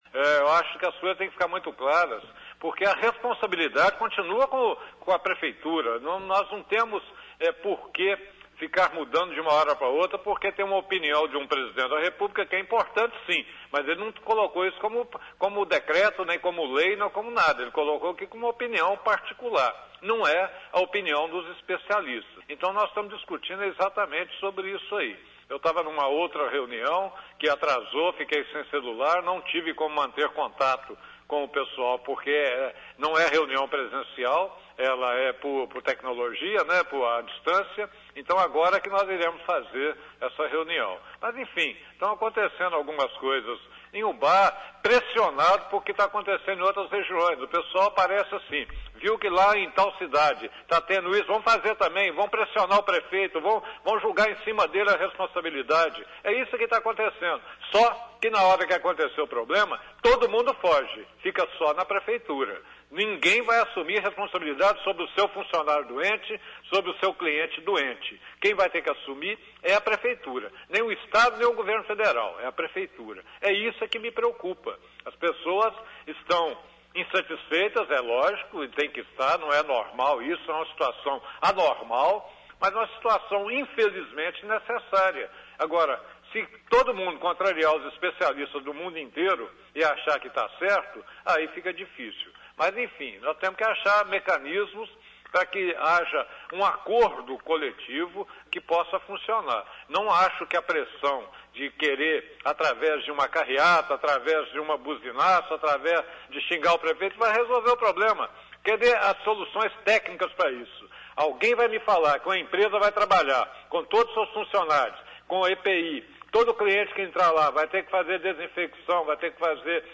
Entrevista exibida na Rádio Educadora AM/FM Ubá-MG